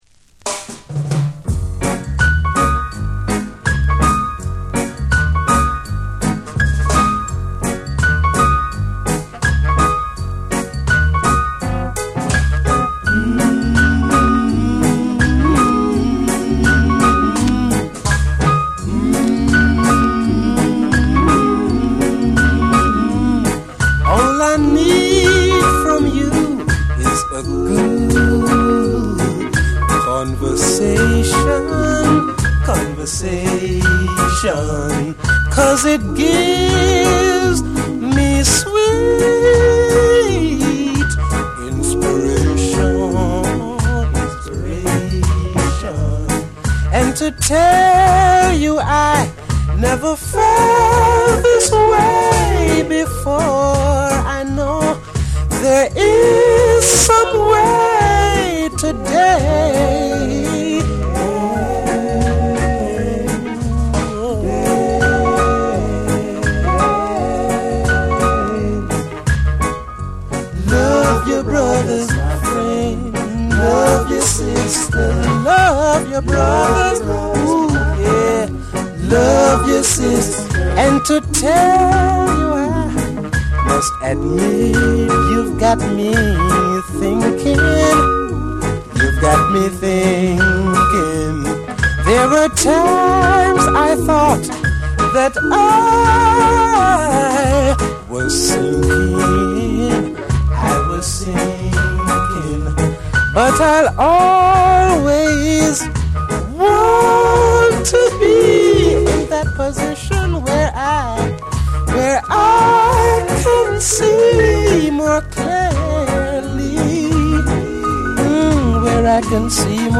ルーツ／ロックステディを横断する甘く切ないハーモニーと、哀愁を帯びたメロディが全編に染み渡る内容。